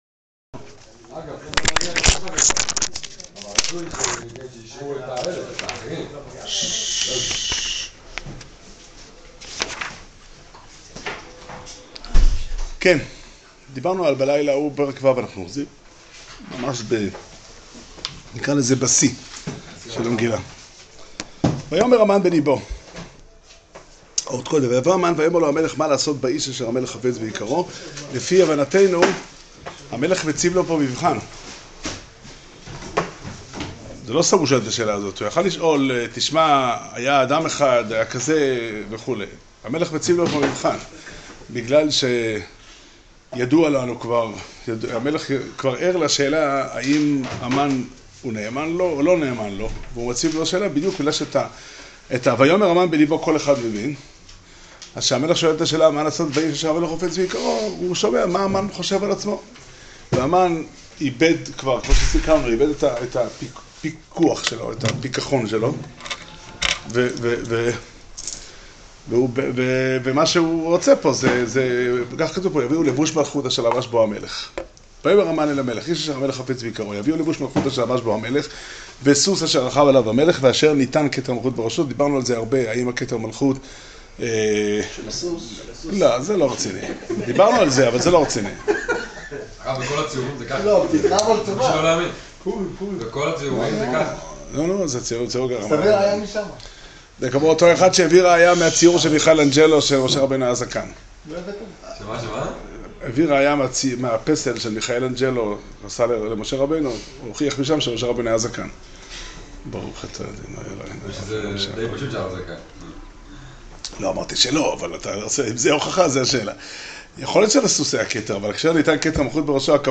שיעור שנמסר בבית המדרש פתחי עולם בתאריך ז' אדר ב' תשע"ט